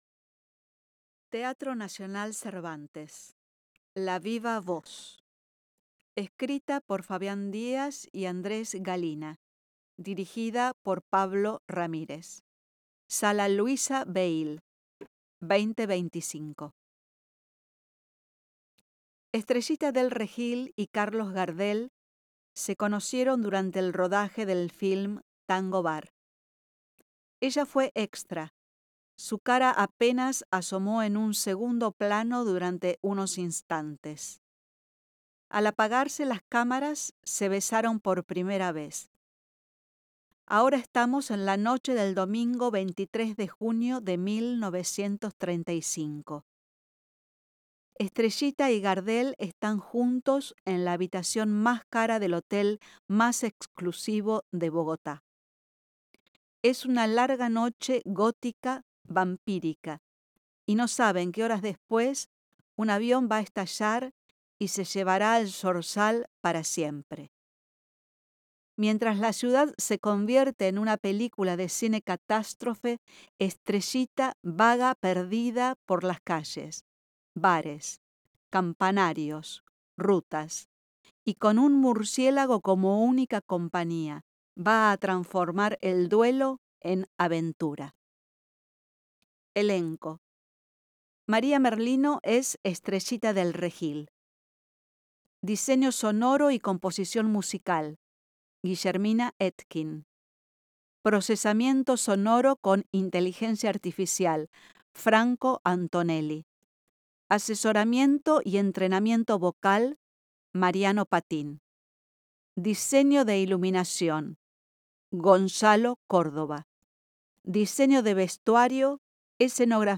El siguiente audio corresponde a lectura del programa de mano del espectáculo La viva voz
Programa-de-mano-accesible-La-viva-voz.mp3